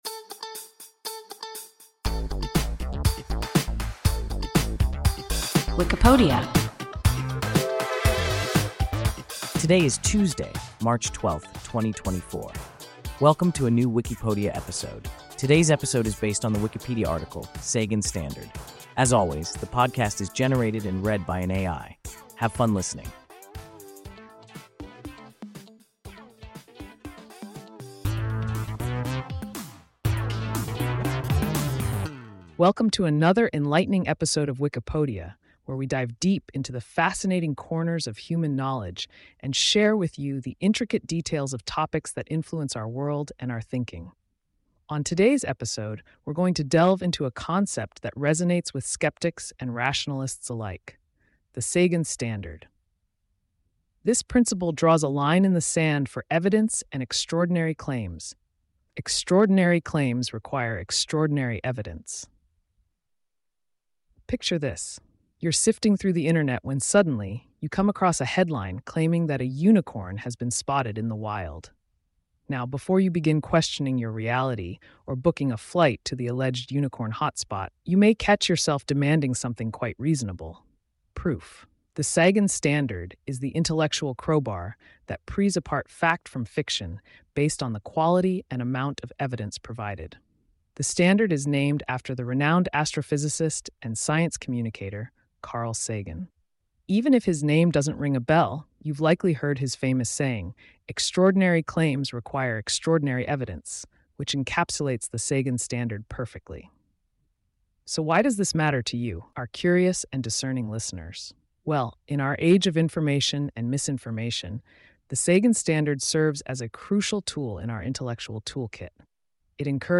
Sagan standard – WIKIPODIA – ein KI Podcast